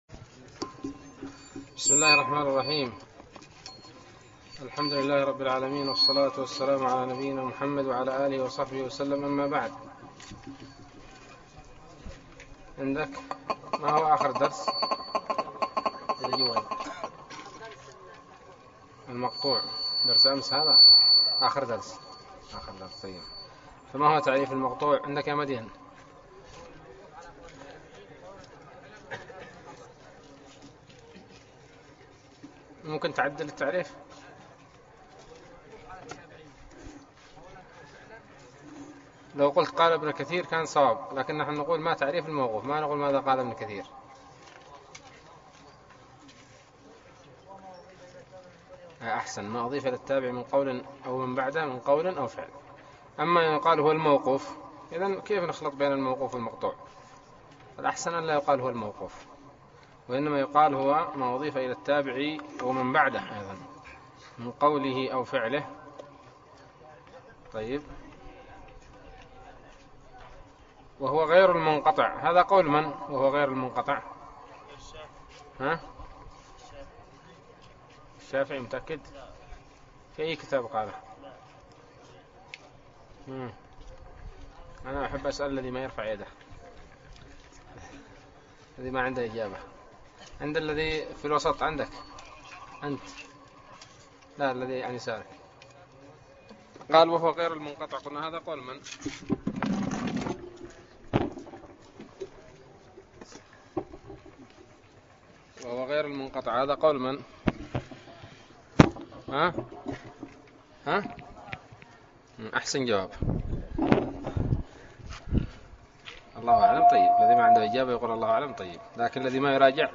الدرس السادس عشر من الباعث الحثيث